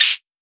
Index of /musicradar/essential-drumkit-samples/Vermona DRM1 Kit
Vermona Closed Hat 03.wav